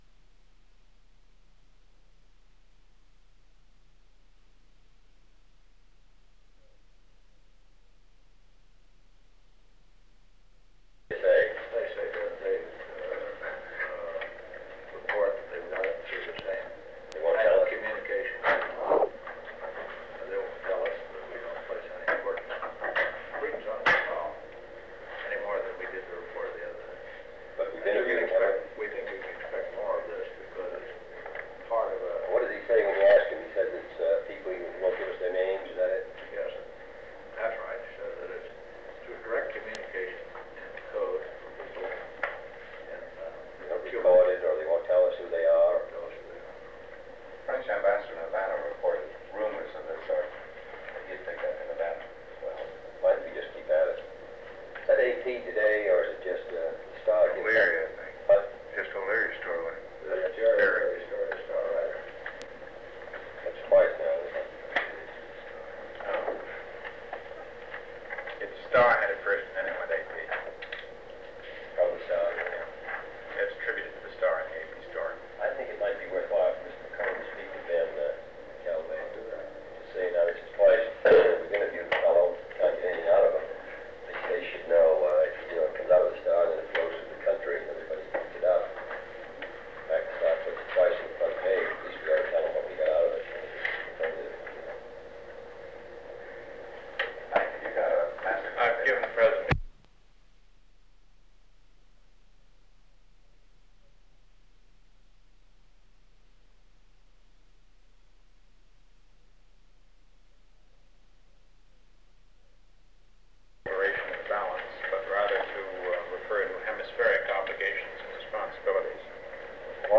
Secret White House Tapes | John F. Kennedy Presidency Executive Committee Meeting of the National Security Council Rewind 10 seconds Play/Pause Fast-forward 10 seconds 0:00 Download audio Previous Meetings: Tape 121/A57.